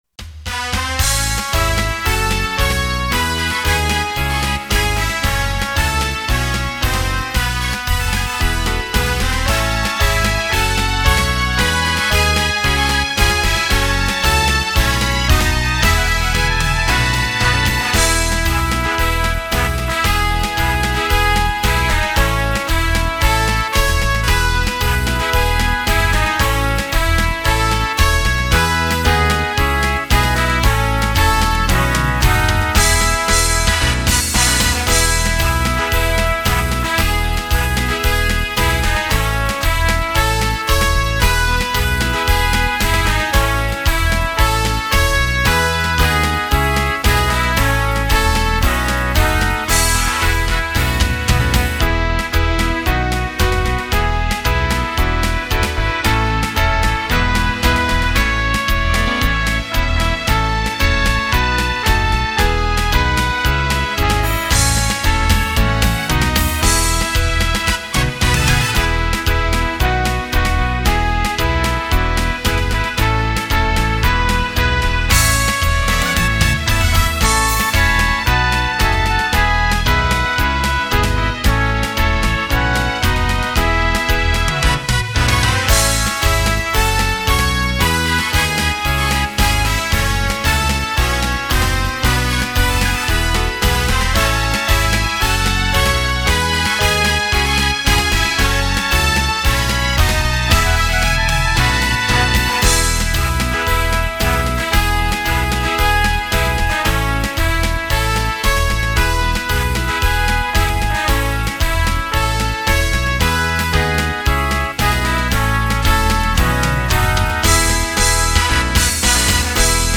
MP3 - Hino Municipal de Peabiru Instrumental (SEM VOZ)